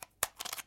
武器（1911年手枪）" 手枪反吹01
标签： 剪辑 重新加载
声道立体声